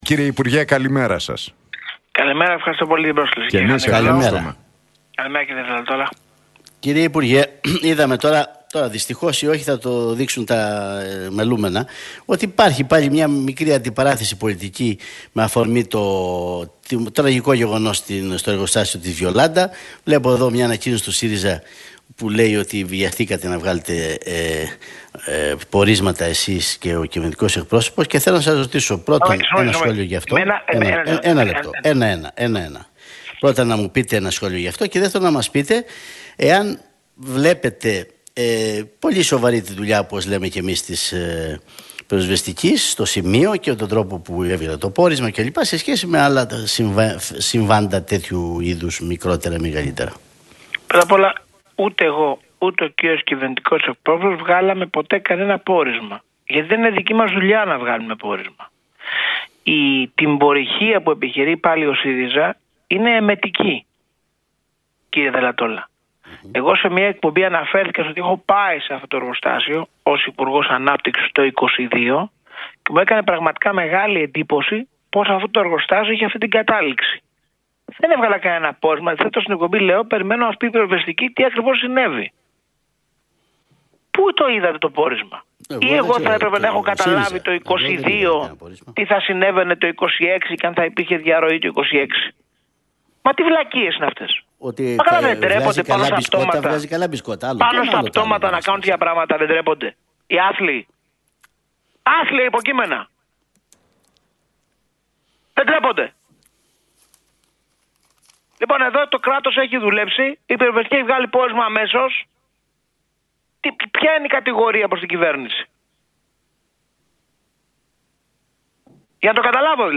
εξαπέλυσε ο Άδωνις Γεωργιάδης μιλώντας στον Realfm 97,8